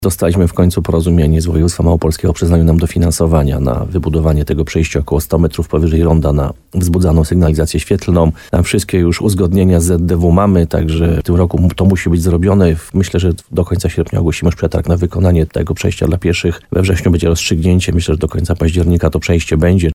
– Teraz piesi nie będą blokować płynnego przejazdu na ulicy Piłsudskiego – mówi burmistrz Piotr Ryba.